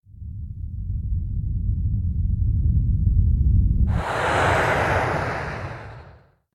rocket_launch.ogg